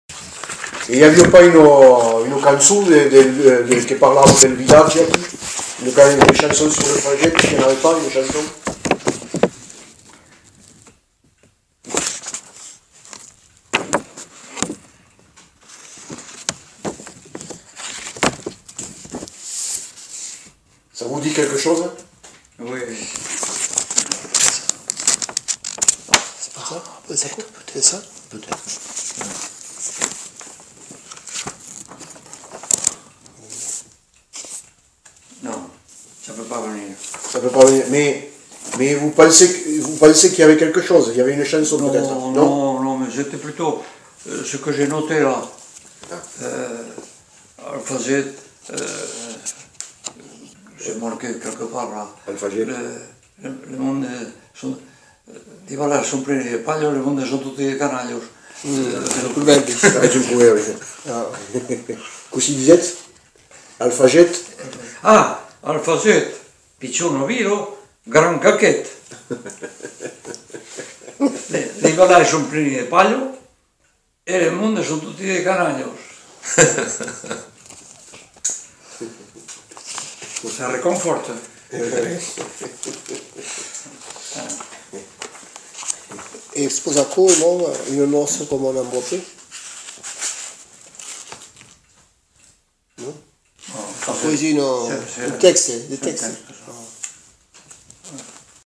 Aire culturelle : Lauragais
Lieu : Le Faget
Genre : forme brève